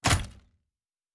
Locker 4.wav